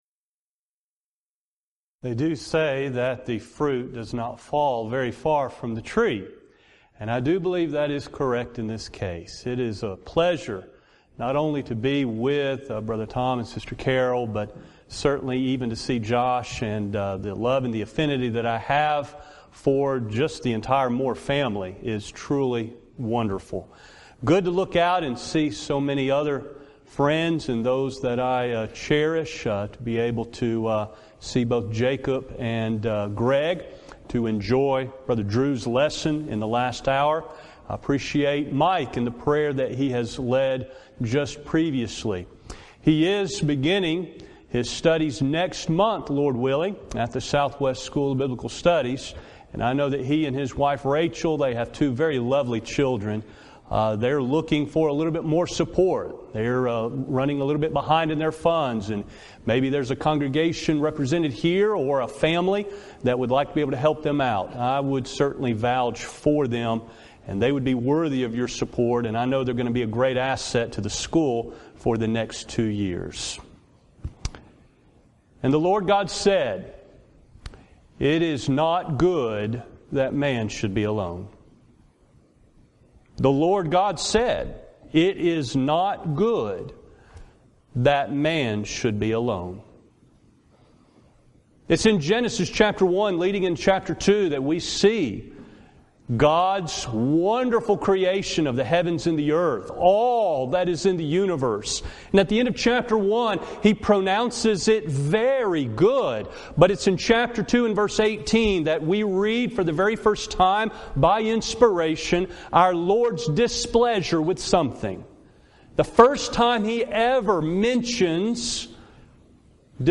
Event: 6th Annual Back to the Bible Lectures
lecture